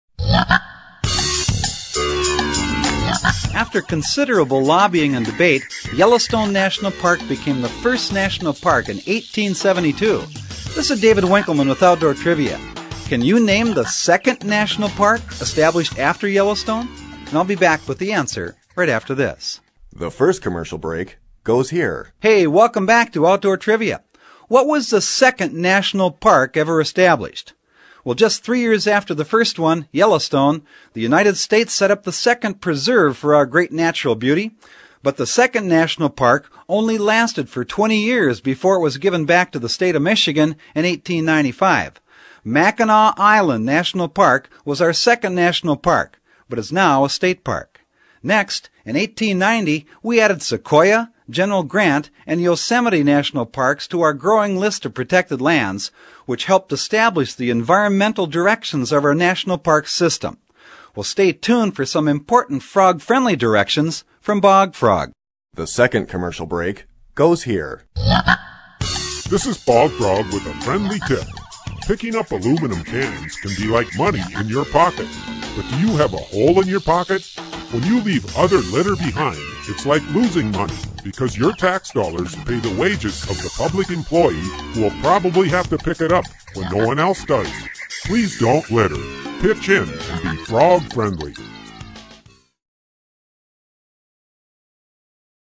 In fact, the question and answer trivia format of this program remains for many people, a most enjoyable, yet practical method of learning.
Bog Frog's voice is distinctive and memorable, while his messages remain positive and practical, giving consumers a meaningful symbol to remember.